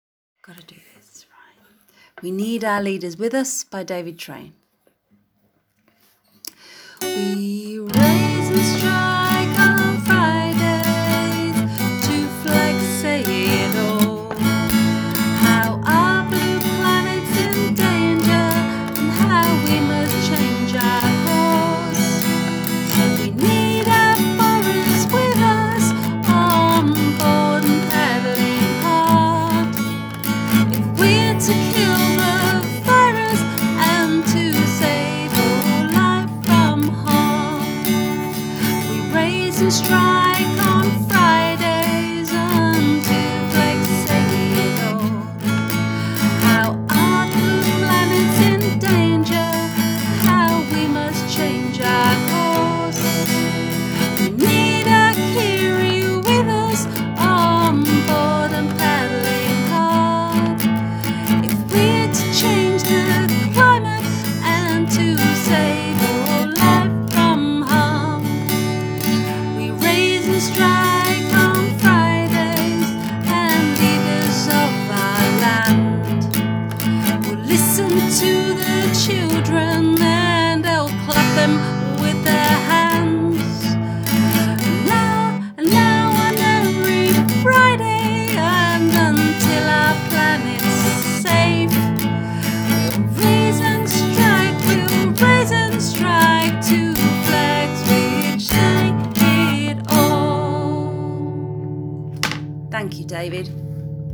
Song: We Need Our Leaders with Us